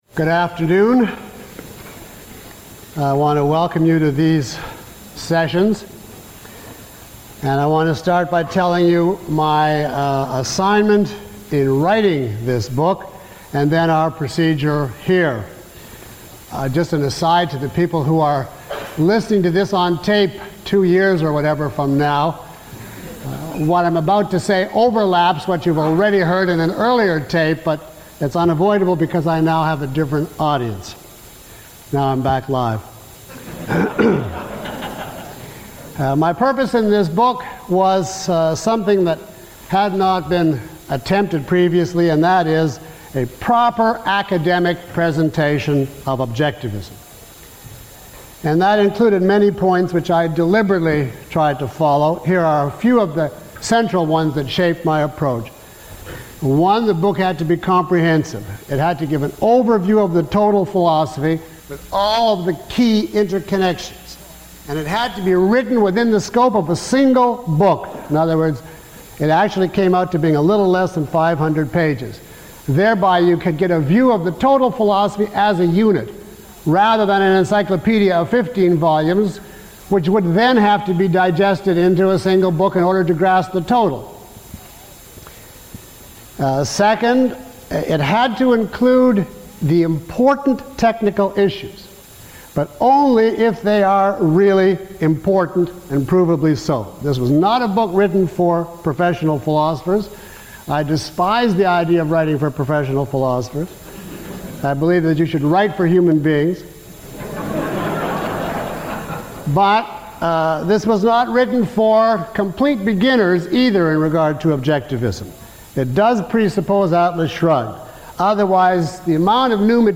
Below is a list of questions from the audience taken from this lecture, along with (approximate) time stamps.
Lecture 10 - Advanced Seminars on Objectivism The Philosophy of Ayn Rand.mp3